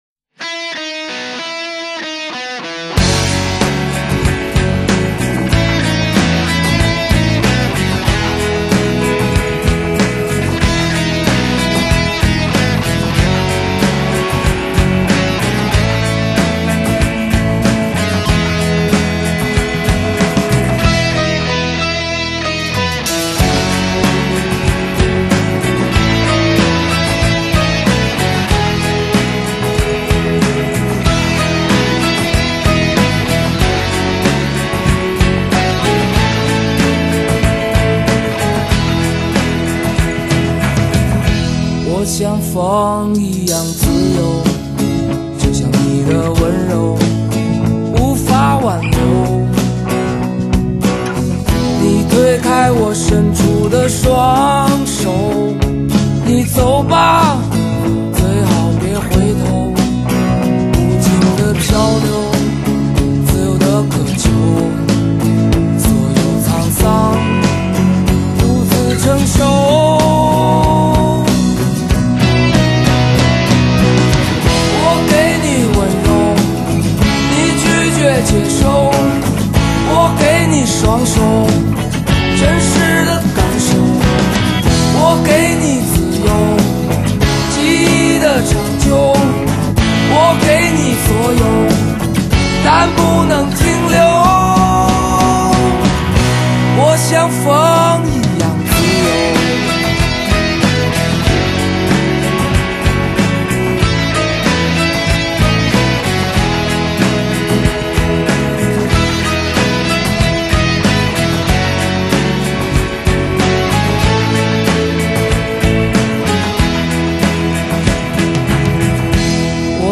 但经过新的编配后，歌曲有了不同的味道：那段吉他动机所主导的情绪予人十分深刻的听觉体验，也使这首简单的歌曲有了扎实的根基。